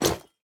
Equip_copper2.ogg